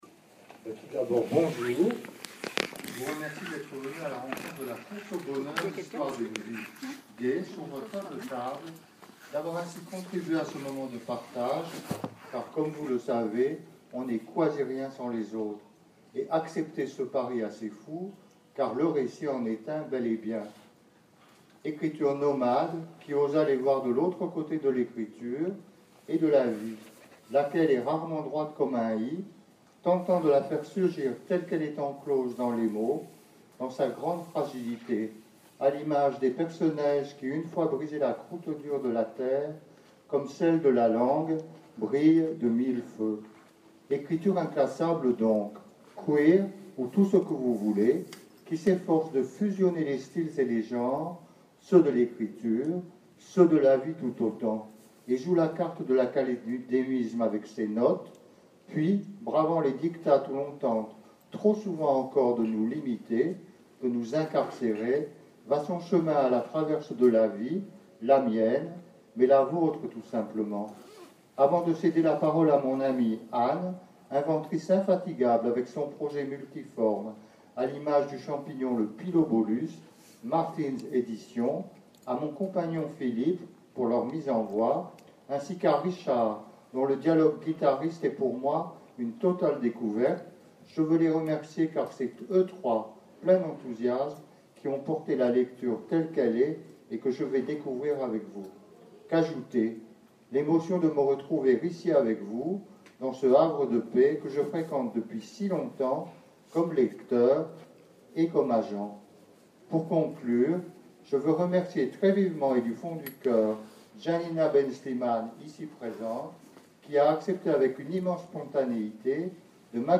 Lecture à la BNF